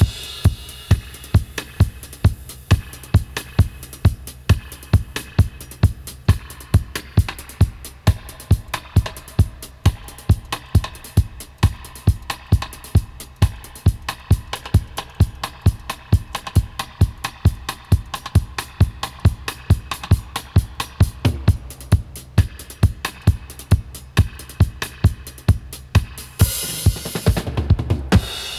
134-DUB-03.wav